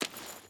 Footsteps / Water
Water Chain Run 5.wav